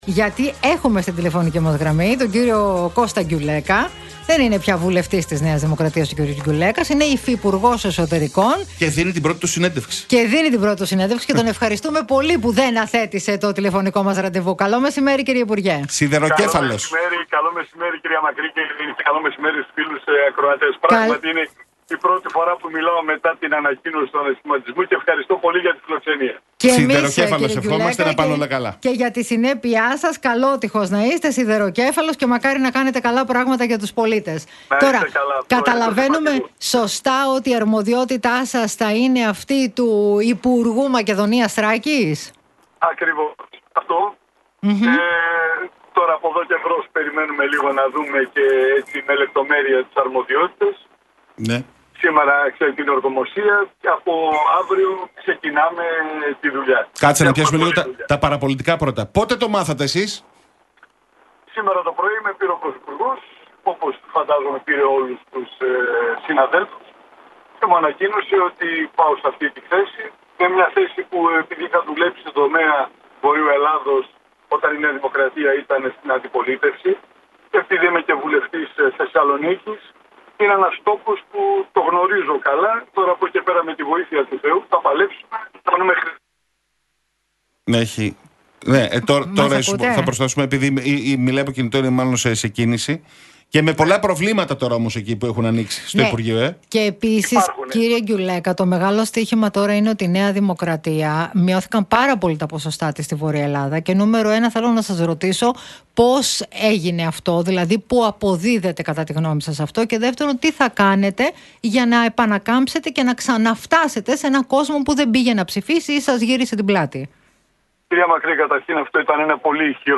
Γκιουλέκας στον Realfm 97,8: Έχουμε δουλειά μπροστά μας – Οι πρώτες δηλώσεις ως υφυπουργός Εσωτερικών